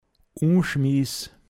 pinzgauer mundart
U(n)schmiiss, m. Unzufriedenheit